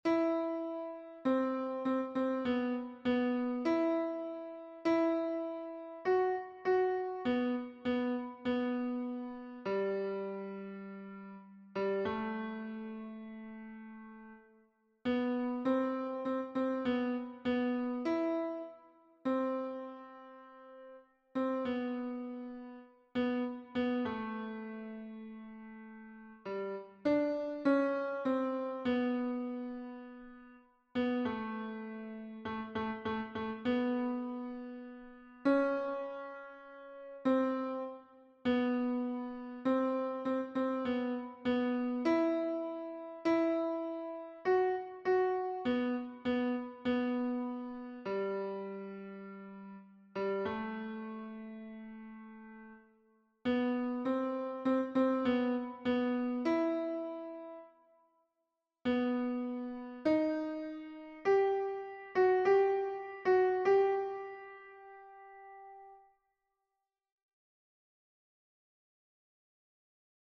Yhtyelaulu 2026